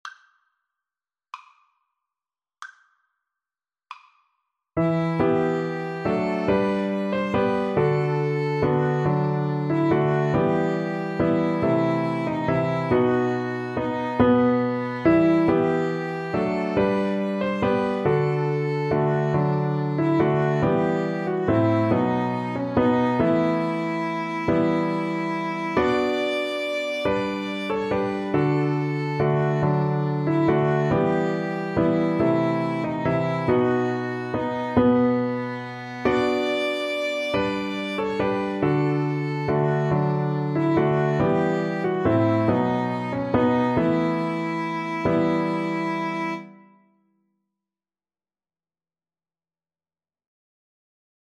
ViolinCelloPiano